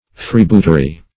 freebootery - definition of freebootery - synonyms, pronunciation, spelling from Free Dictionary
Search Result for " freebootery" : The Collaborative International Dictionary of English v.0.48: Freebootery \Free"boot`er*y\, n. The act, practice, or gains of a freebooter; freebooting.